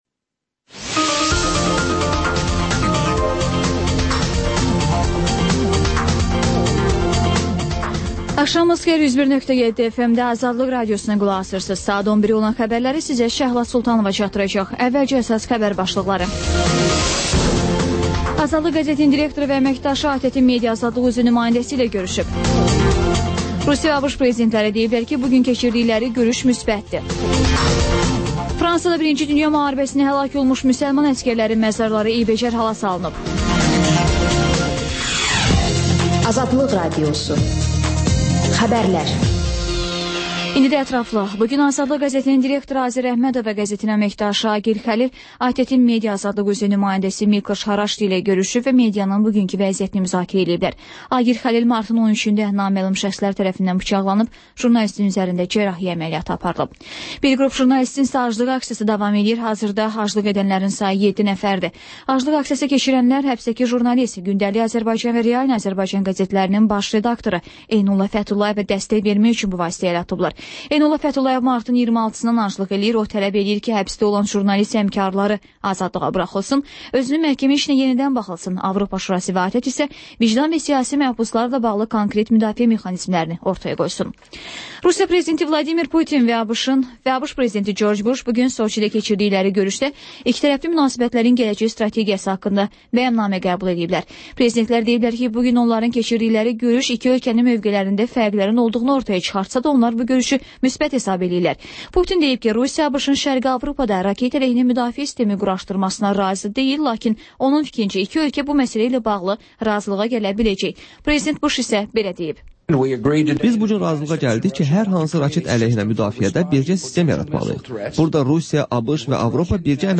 Xəbərlər, İZ: Mədəniyyət proqramı və TANINMIŞLAR verilişi: Ölkənin tanınmış simalarıyla söhbət